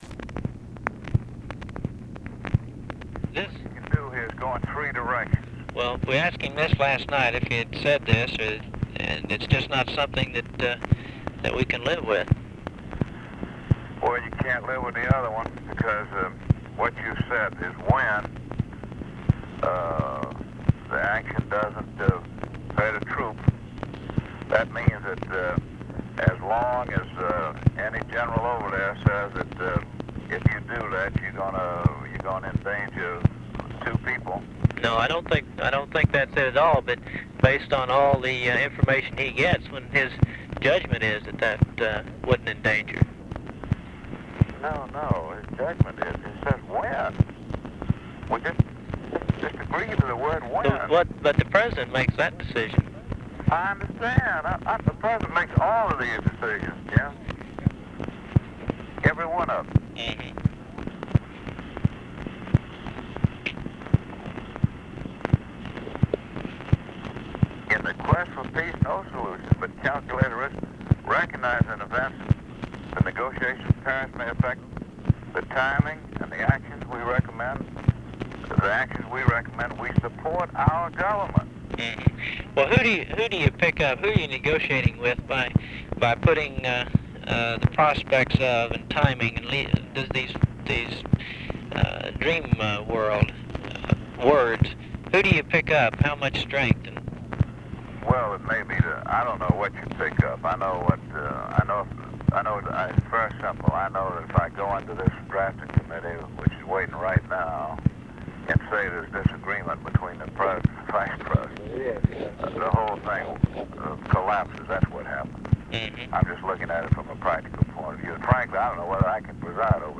Conversation with CHARLES MURPHY, JIM JONES and HALE BOGGS, August 26, 1968
Secret White House Tapes